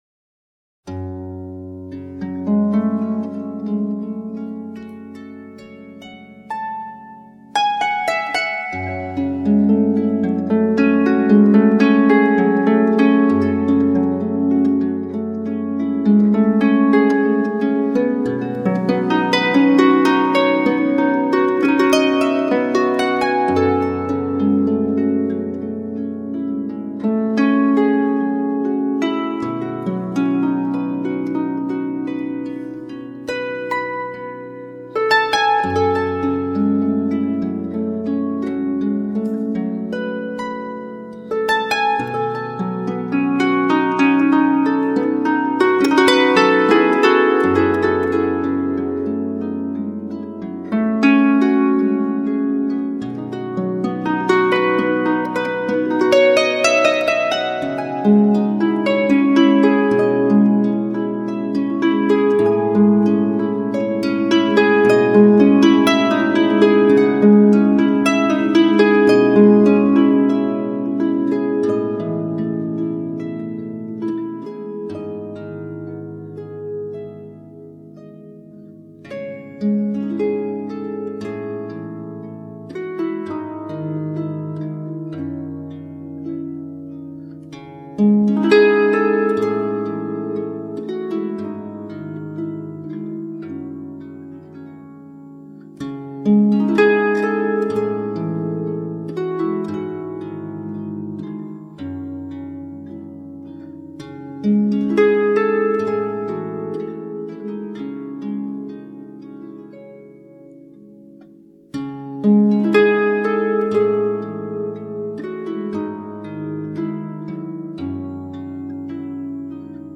音乐类型： New Age， Instrumental